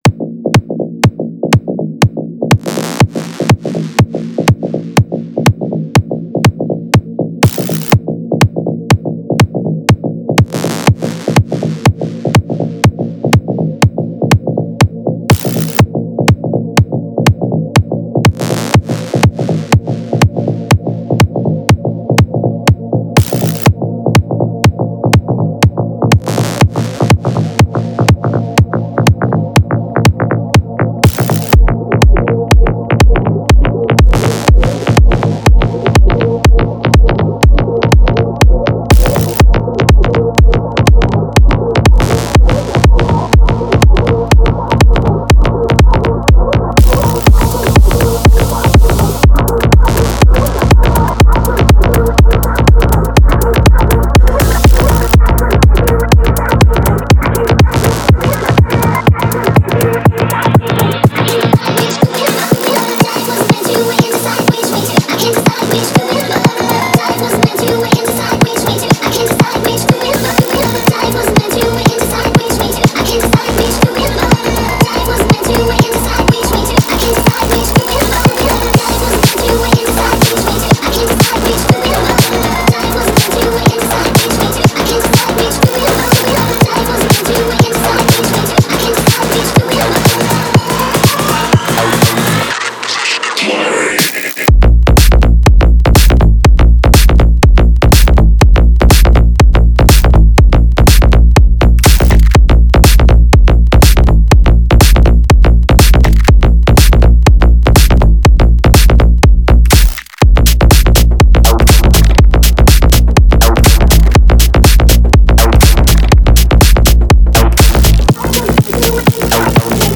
• Жанр: EDM